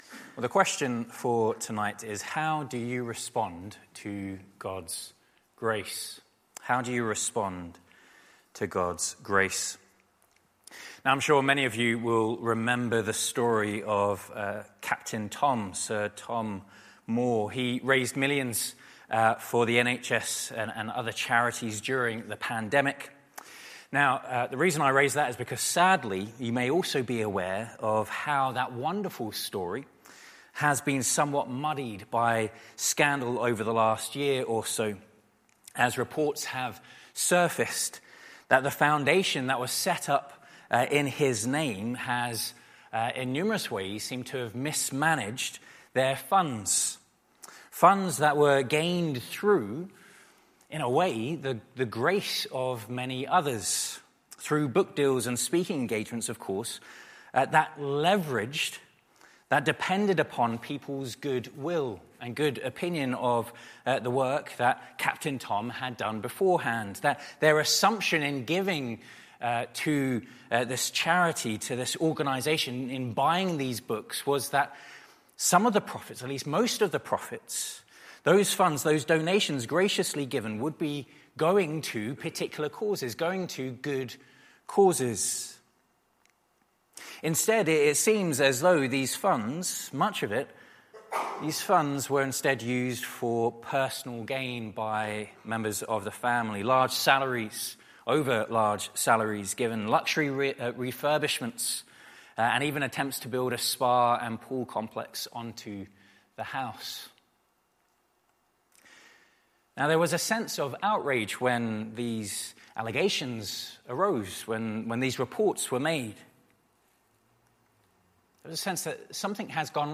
Service Morning Service